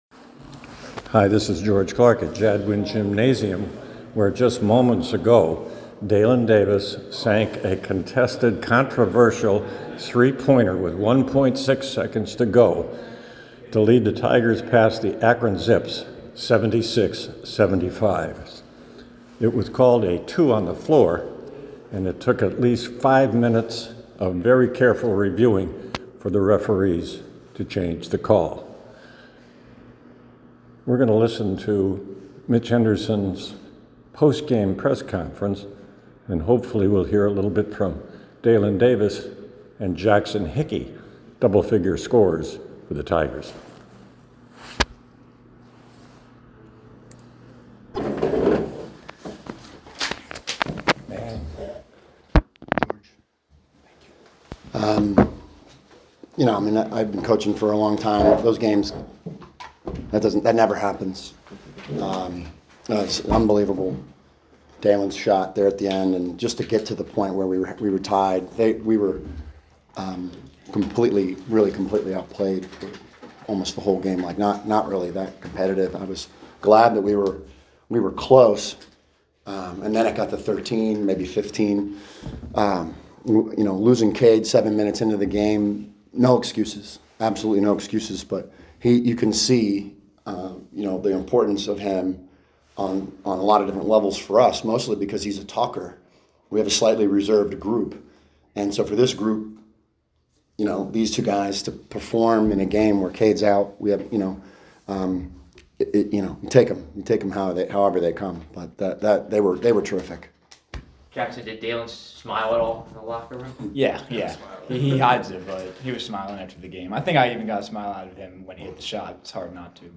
LISTEN: Princeton men’s basketball’s postgame presser after 76-75 win over Akron
Akron-presser.mp3